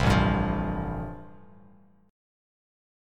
Am7#5 chord